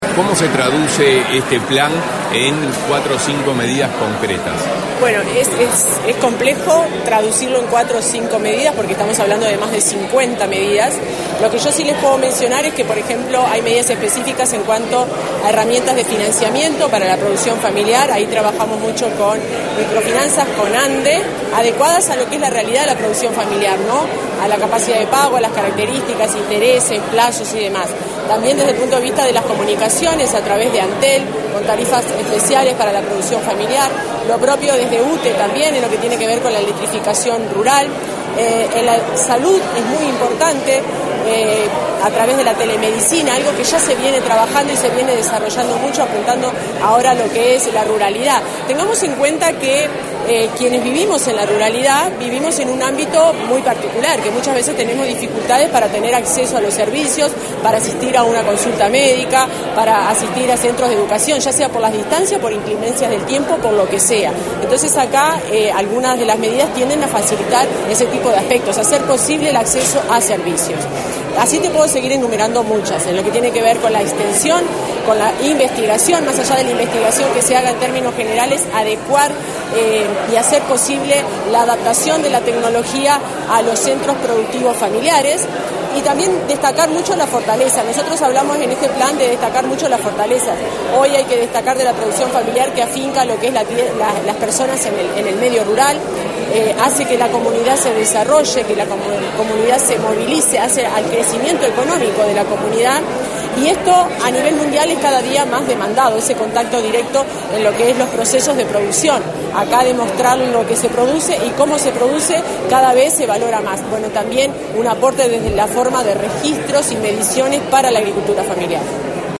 Declaraciones a la prensa de la directora general del MGAP, Fernanda Maldonado
Declaraciones a la prensa de la directora general del MGAP, Fernanda Maldonado 12/06/2024 Compartir Facebook X Copiar enlace WhatsApp LinkedIn Tras participar en el acto de presentación del Plan Nacional de Agricultura Familiar 2024 -2028, con la presencia del presidente de la República, Luis Lacalle Pou, la directora del Ministerio de Ganadería, Agricultura y Pesca (MGAP), Fernanda Maldonado, realizó declaraciones a la prensa.